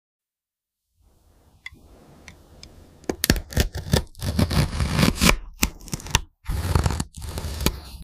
part 35 | AI ASMR sound effects free download
part 35 | AI ASMR video for cutting Porche gt3 rs car